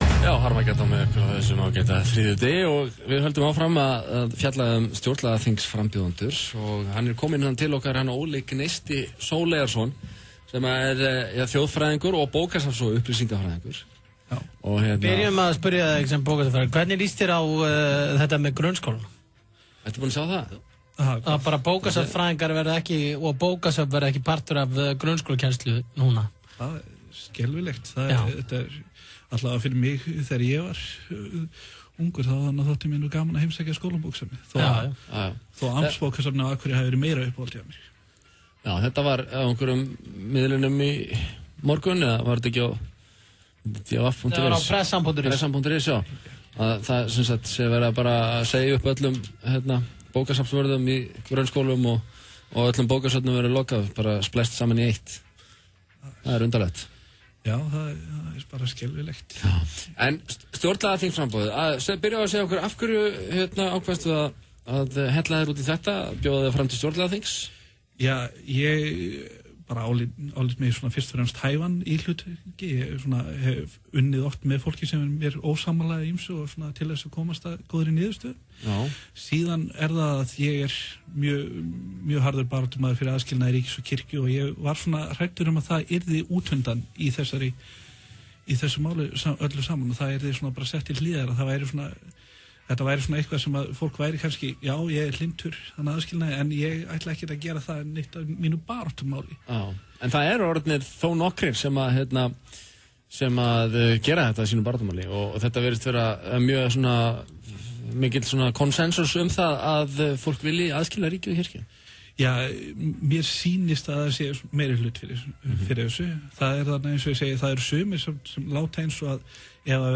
Á þriðjudaginn kom ég í þættinum Harmageddon á X-inu til að ræða framboð mitt. Þetta var bæði skemmtilegra og meira ögrandi heldur en örviðtalið á RÚV.